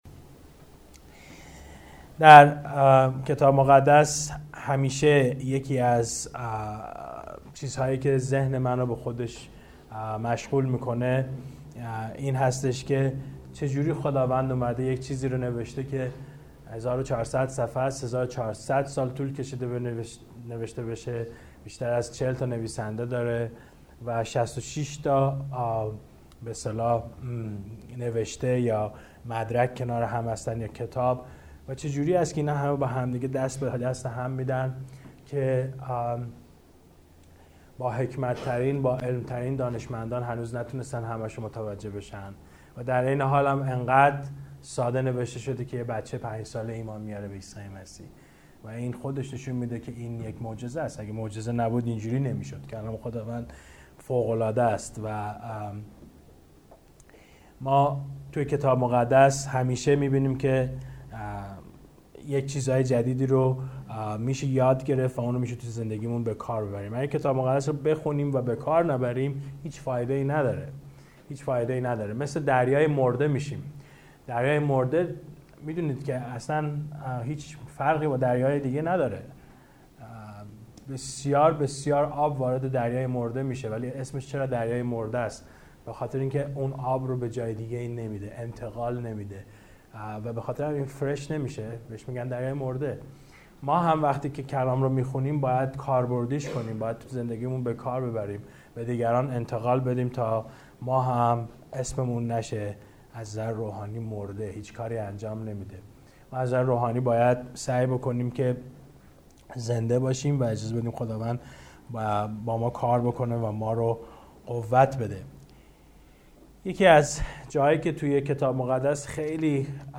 موعظه: وسوسه، حسادت و کار گروهی – انستیتو کتاب مقدس پارسیان